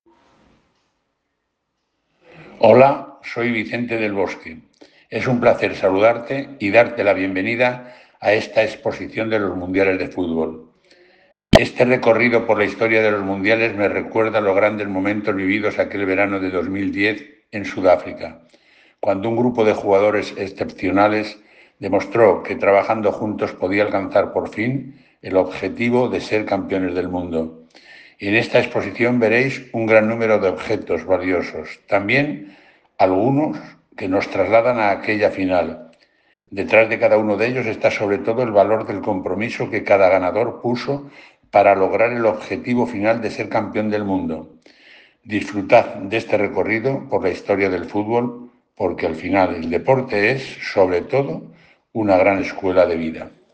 Escucha a Vicene del Bosque dar la bienvenida a la exposición de los mundiales